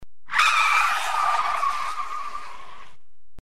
Звуки тормозов машины
На этой странице собраны звуки тормозов машин в разных ситуациях: от резкого экстренного торможения до плавного замедления.